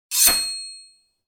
SWORD_25.wav